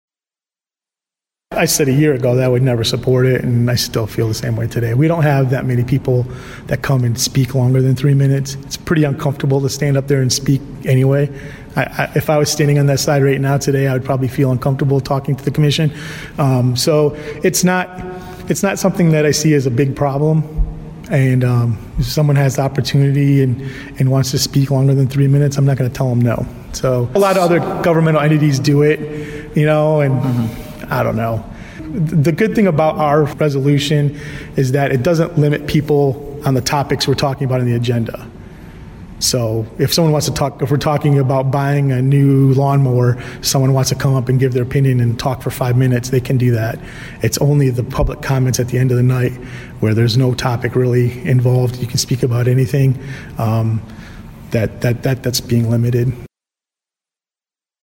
WLEN News talked to Mayor Jacobson after the vote…